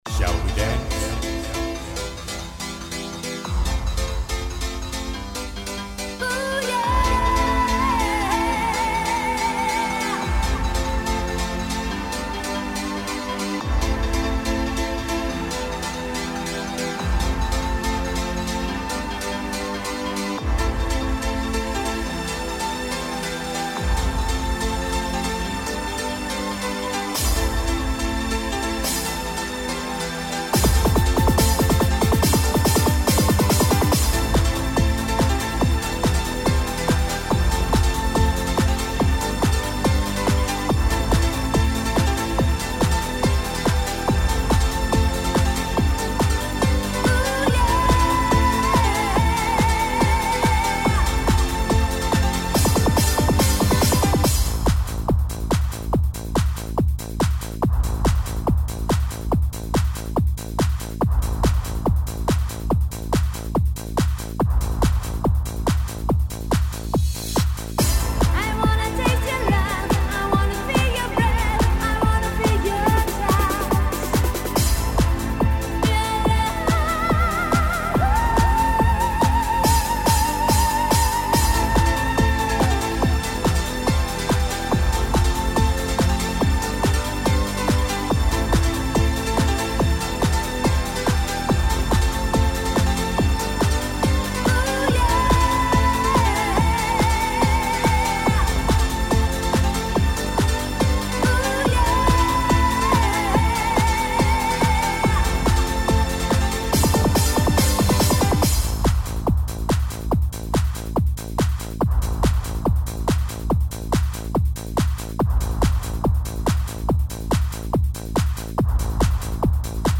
Shall we dance Das erste Mal, dass ich auch Stimm-Samples mit eingebastelt hab